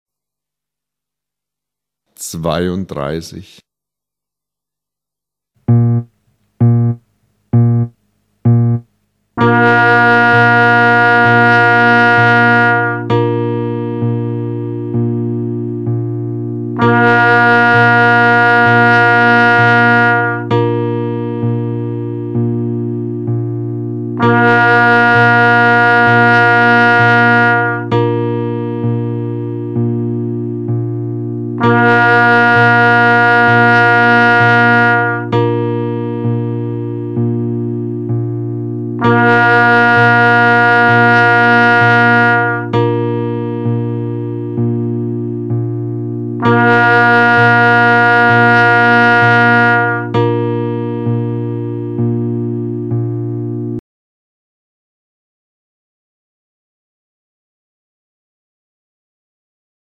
Töne auf dem Horn
Nun versuche, die Töne der folgenden Übung möglichst präzise (wie im Klangbeispiel 32 ) anzustoßen !